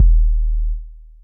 Classic South 808.wav